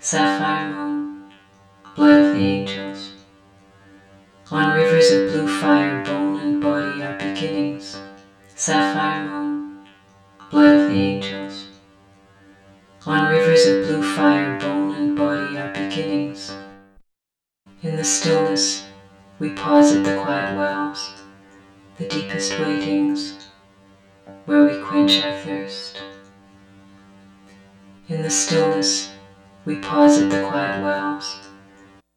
Source: Text x2 (4:43-5:15)
Processing: stereo + KS 305/304 (G); feedback 950/960, input 100->max->100